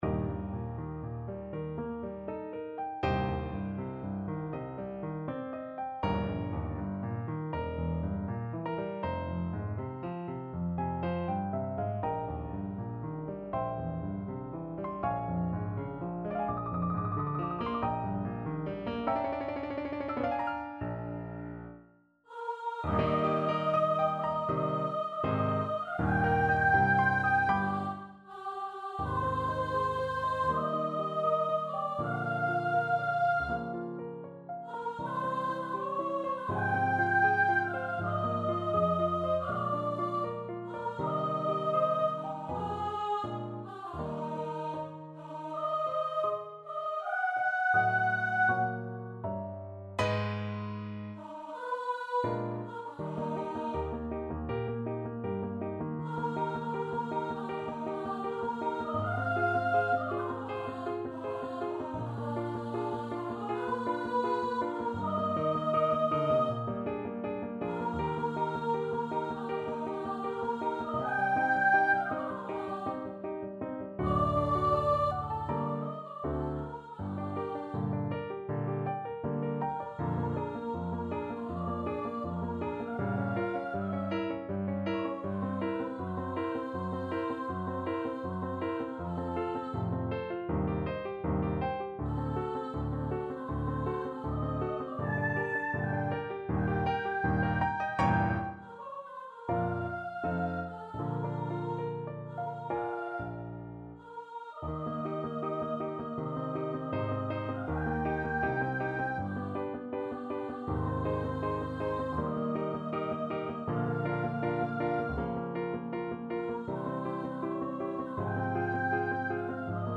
4/4 (View more 4/4 Music)
Classical (View more Classical Voice Music)